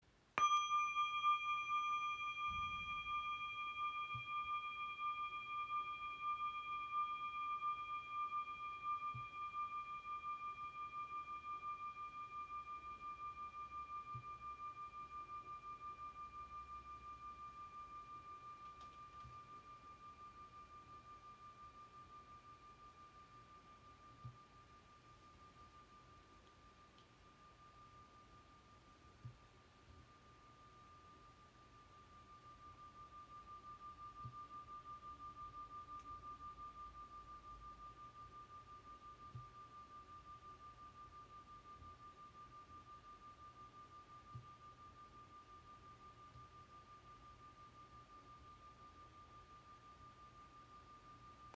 • Icon Sehr lange Resonanz mit besonders reinen Schwingungen
Schwebende Klangglocken | Ausführung Cosmos | In der Grösse 6 cm
Handgeschmiedete Klangglocken in der Ausführung Cosmo mit leuchtenden kosmischen Obertönen.
Ihr Klang ist klar, lichtvoll und rein.